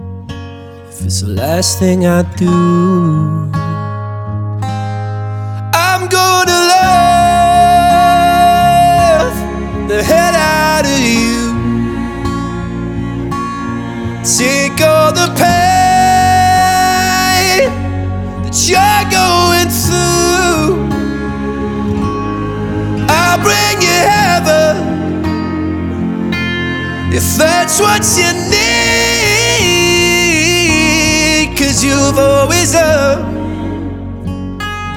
Жанр: Альтернатива
# Alternative